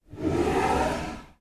Bei einem wish (tweet contains “wish”) ertönt bei uns (im Wohnzimmer…) das Geräusch einer schliessenden Schublade mit schwerem Atem